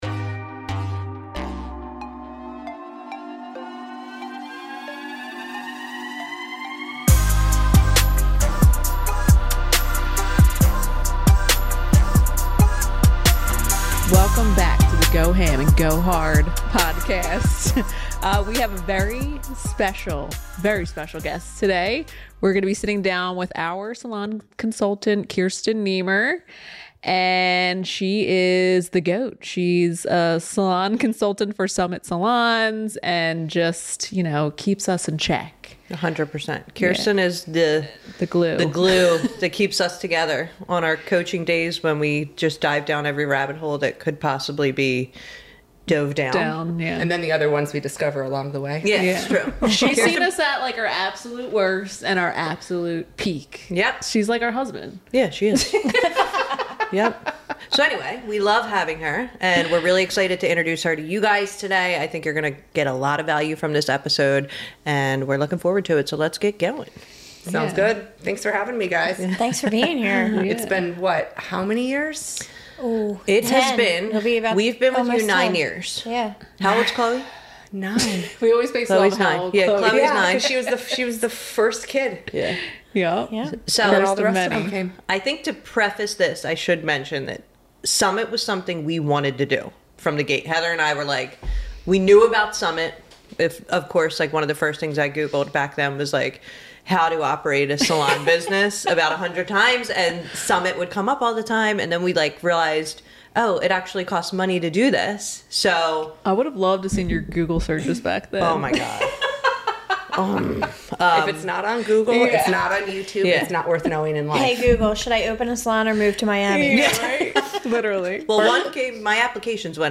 Whether you're struggling with leadership, culture, or growth, this conversation is packed with insights to help you overcome obstacles and take your business to the next level.